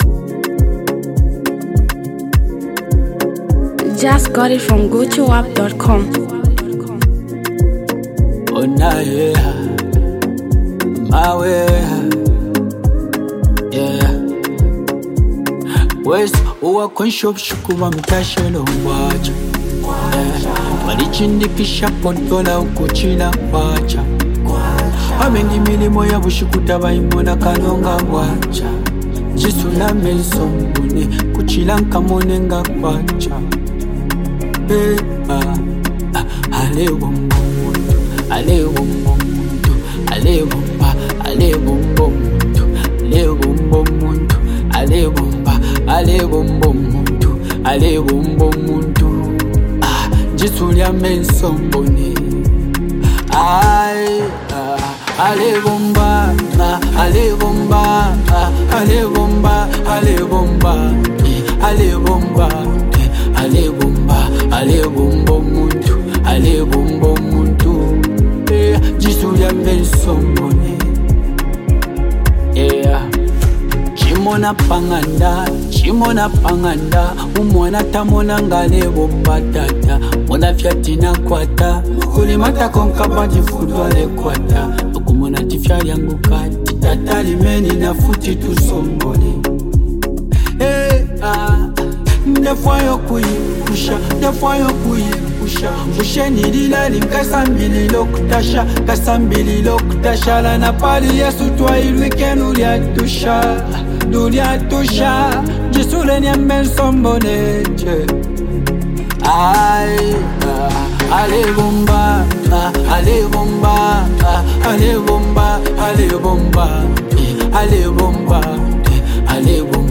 Zambian Mp3 Music
political anthem